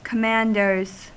Ääntäminen
Ääntäminen US Haettu sana löytyi näillä lähdekielillä: englanti Käännöksiä ei löytynyt valitulle kohdekielelle. Commanders on sanan commander monikko.